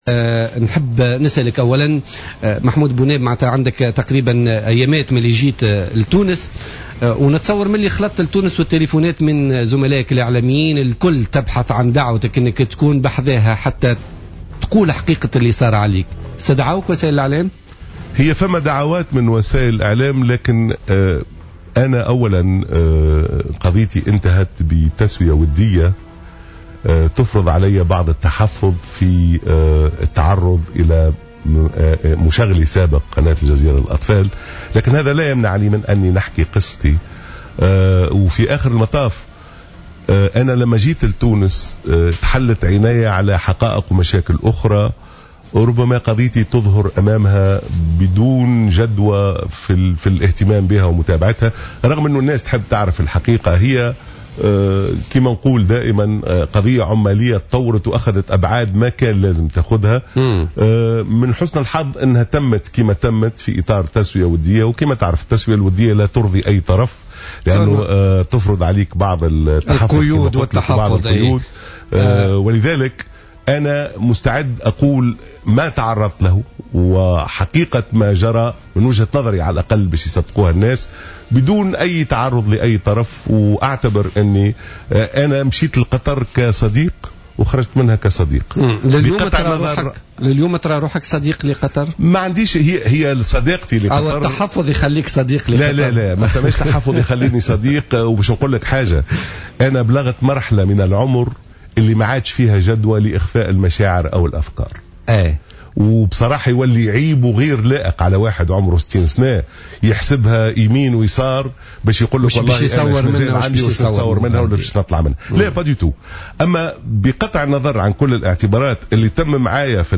lors d’une interview exclusive accordée à Jawhara FM